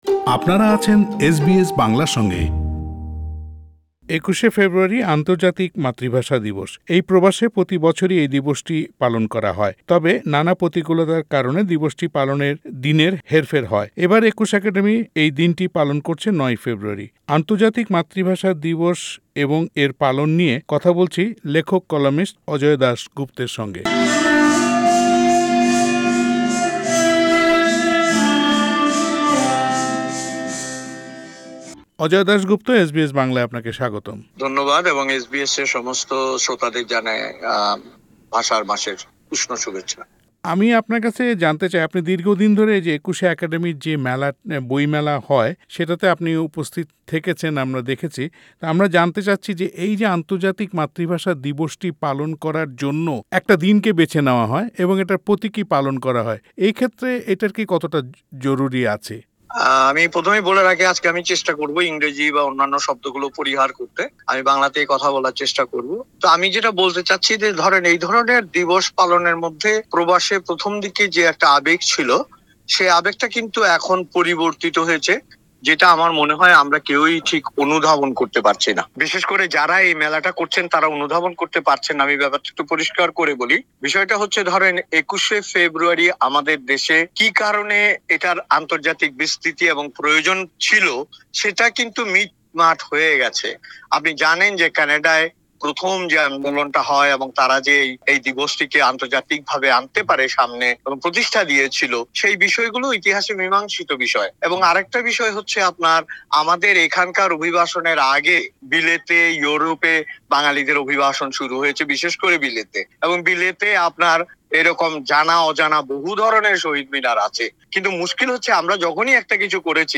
পুরো সাক্ষাৎকারটি বাংলায় শুনতে উপরের অডিও প্লেয়ারটিতে ক্লিক করুন।